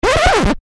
Звуки скретча
Звук царапания виниловой пластинки при резкой остановке рукой